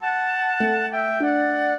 flute-harp
minuet3-4.wav